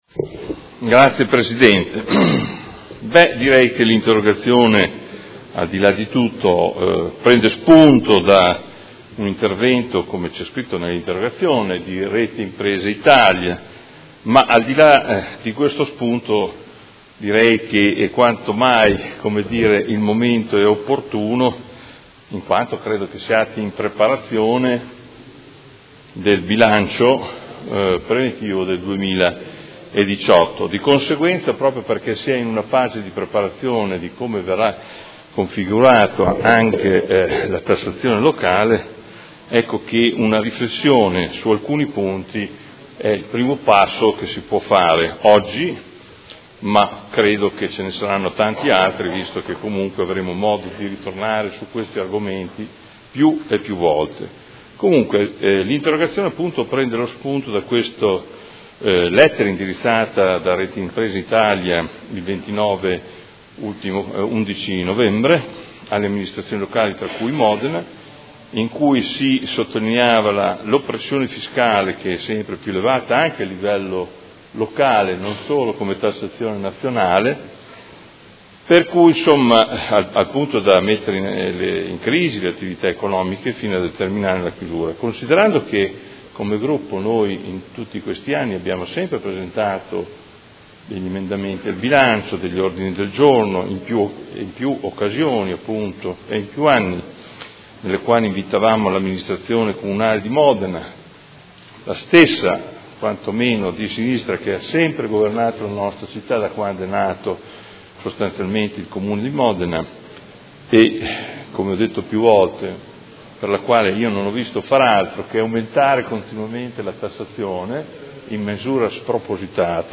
Adolfo Morandi — Sito Audio Consiglio Comunale
Seduta del 21/12/2017. Interrogazione del Consigliere Morandi (FI) avente per oggetto: Rivedere e ridurre la tassazione sulle imprese per dare nuovo slancio all'economia cittadina